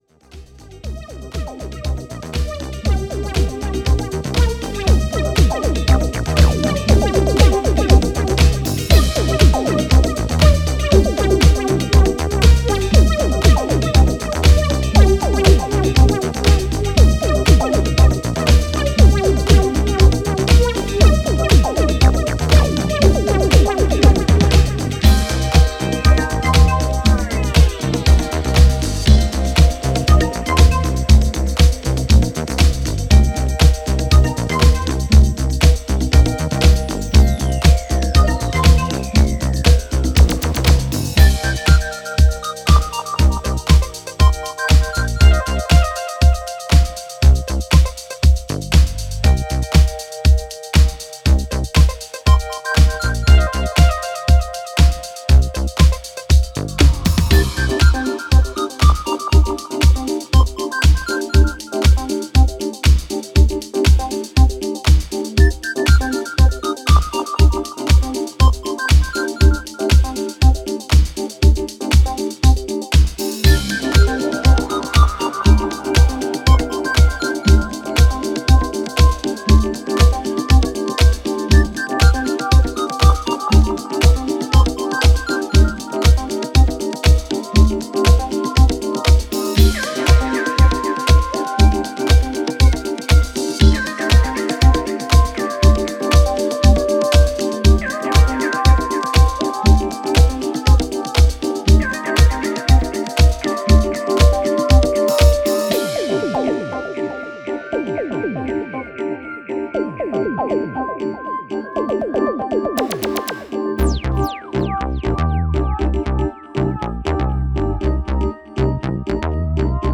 blend funk, Italo, Hi-Nrg disco all into one sound